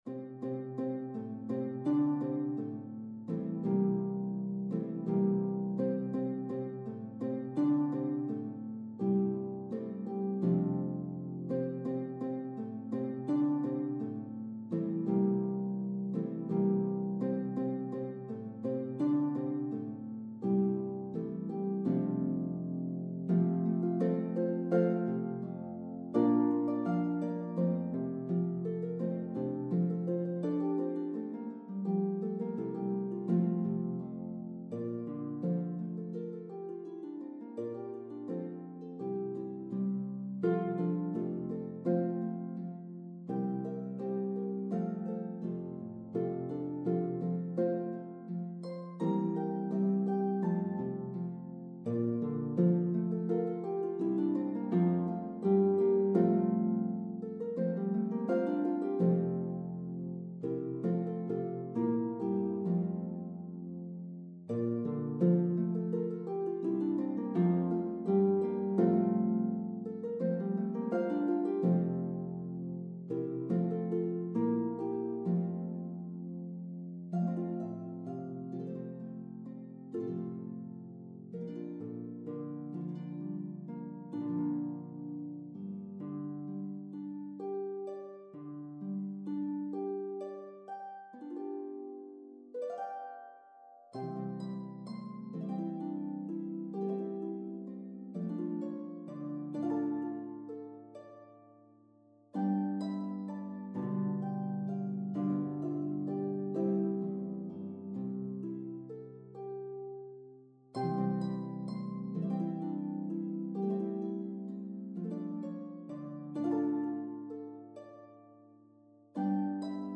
A delightful medley of songs
The melody is equally divided between parts.